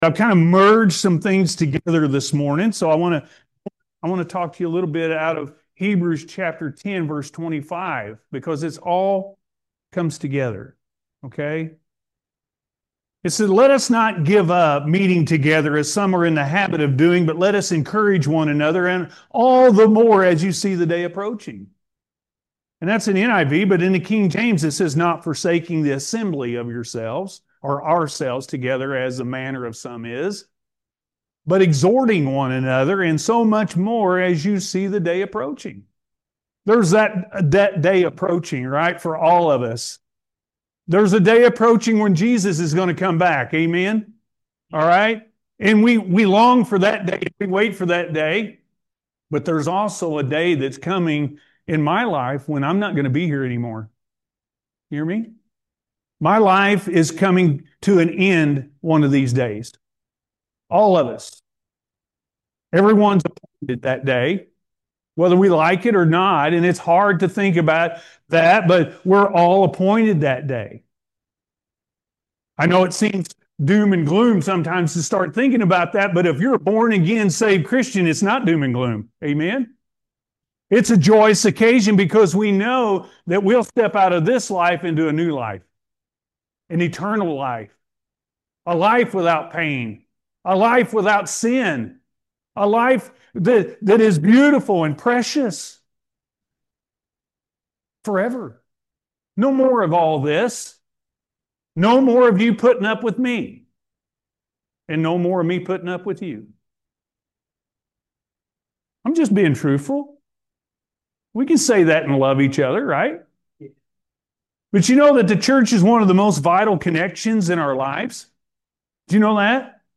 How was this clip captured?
Surrender To The Power Of The Holy Spirit-A.M. Service